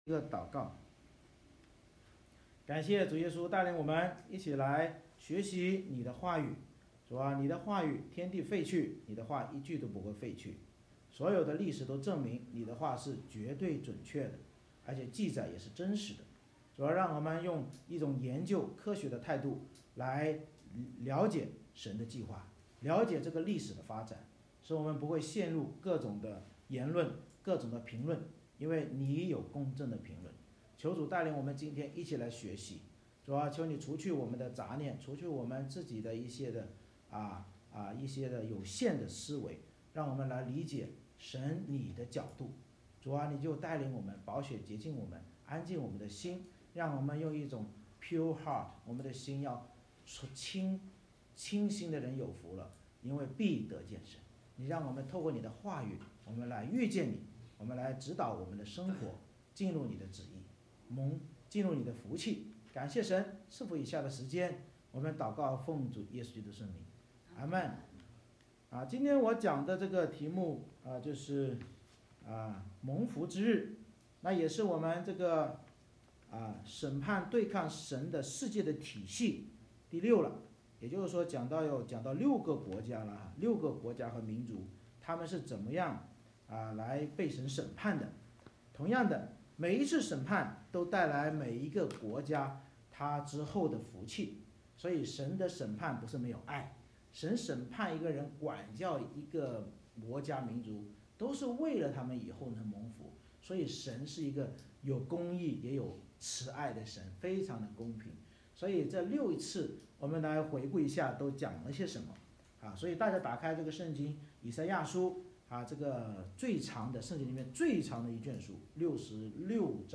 以赛亚书19:1-25 Service Type: 主日崇拜 先知领受埃及的默示